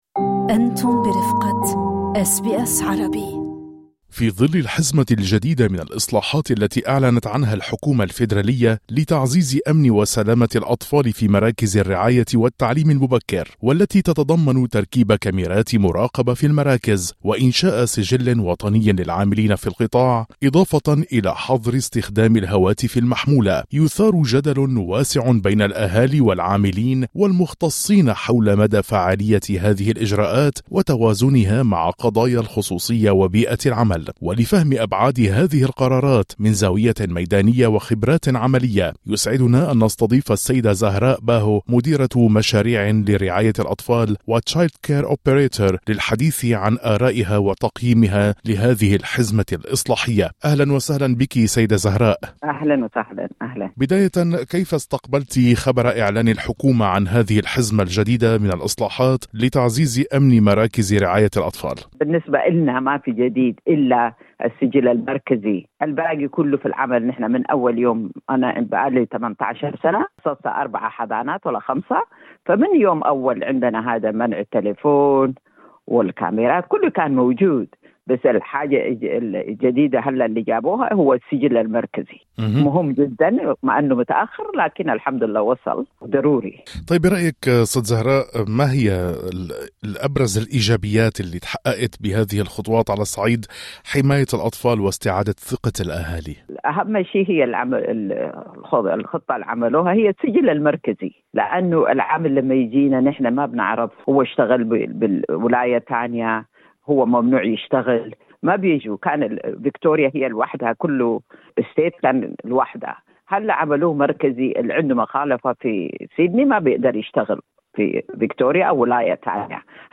خبيرة رعاية